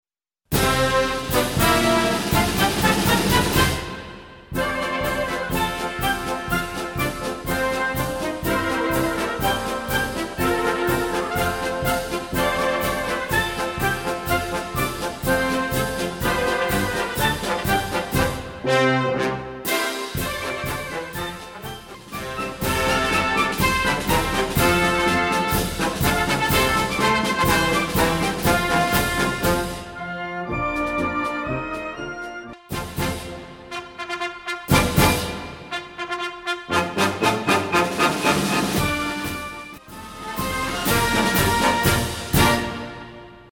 内容解説 オーソドックスなオリジナルマーチです
難易度 分類 並足１２２ 時間 ２分４４秒
編成内容 大太鼓、中太鼓、小太鼓、シンバル 作成No １０４